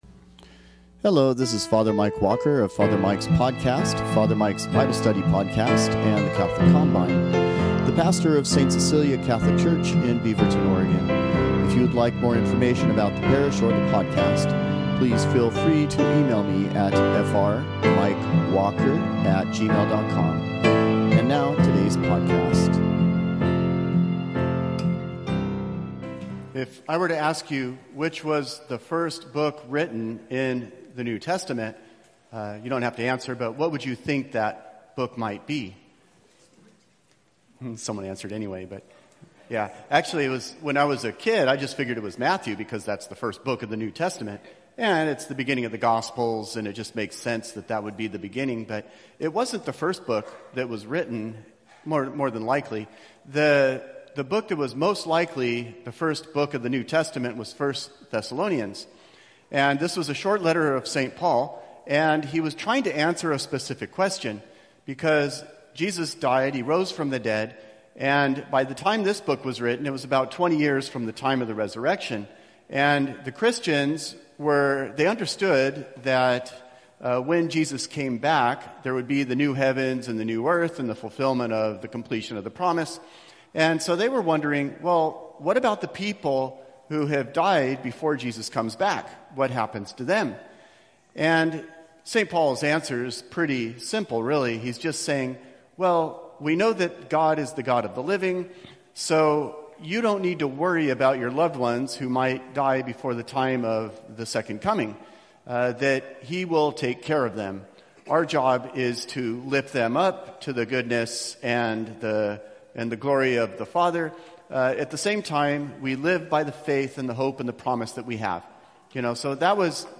Sunday homilies